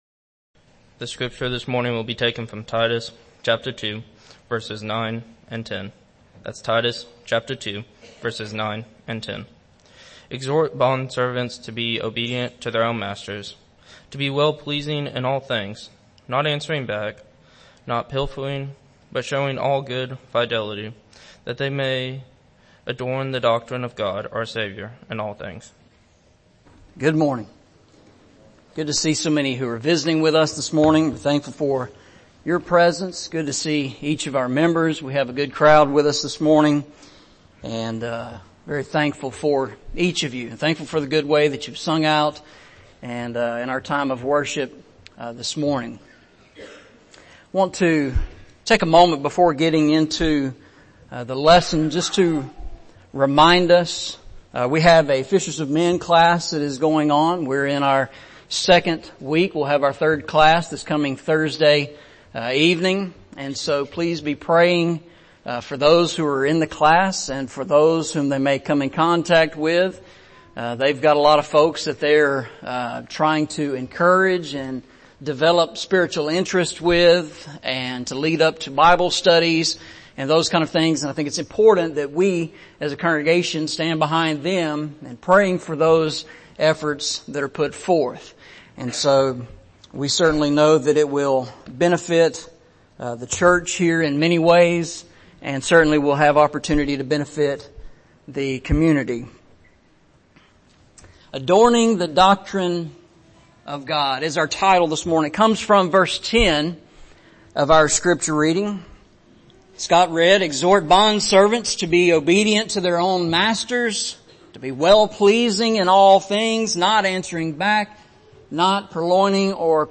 Eastside Sermons Service Type: Sunday Morning Preacher